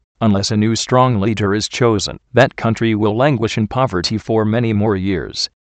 Location: USA